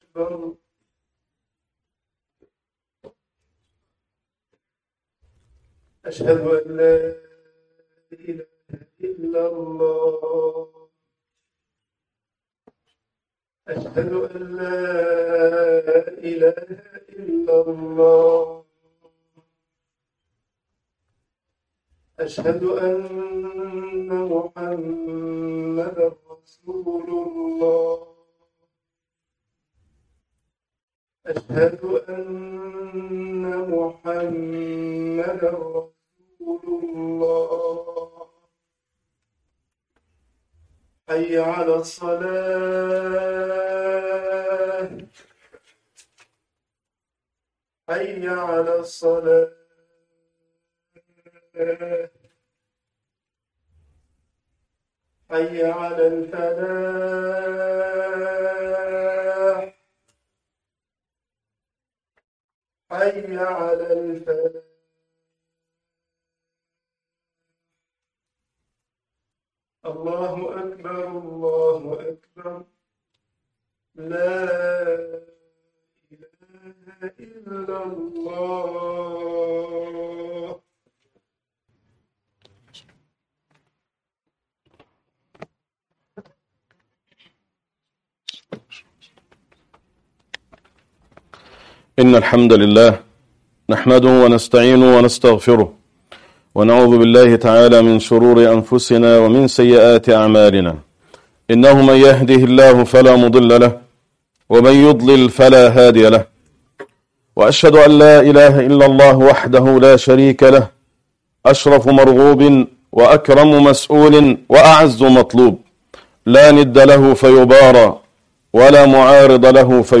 خطبة الجمعة معالم الخير في رمضان